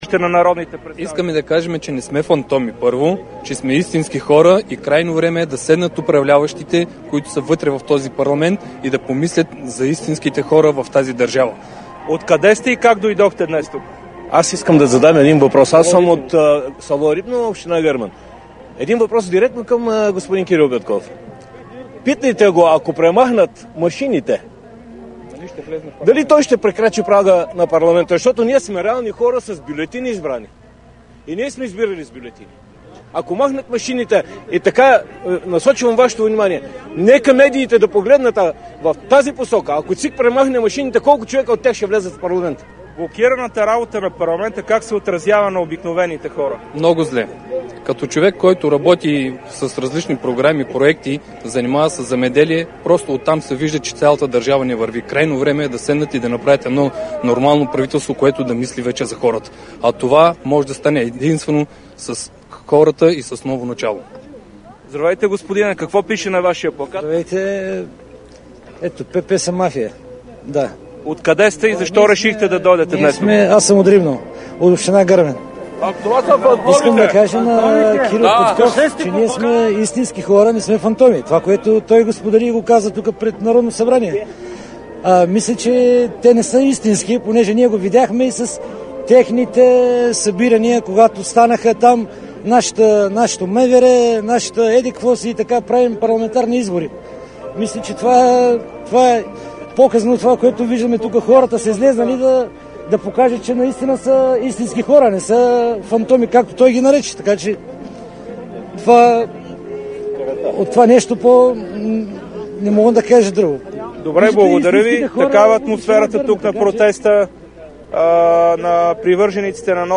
10.40 - Протест на ДПС Ново начало. - директно от мястото на събитието (пред Народното събрание)